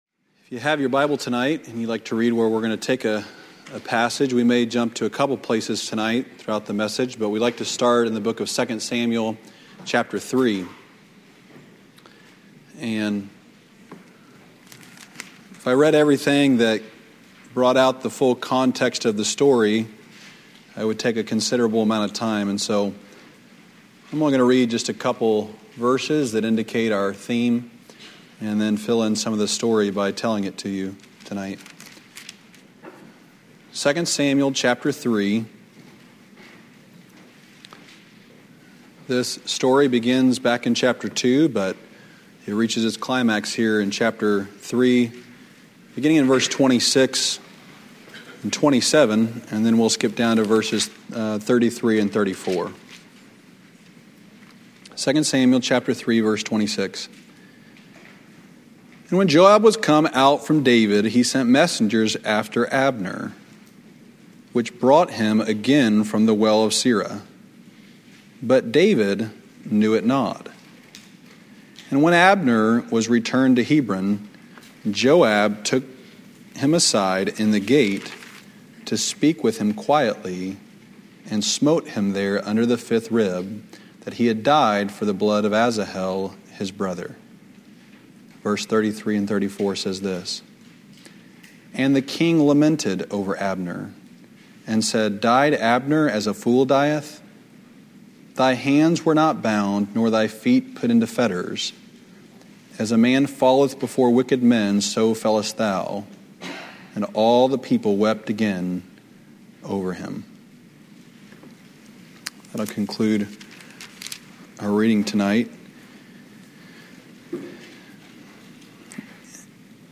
From Series: "Revival Sermons"
Sermons preached during special evening services - usually evangelistic.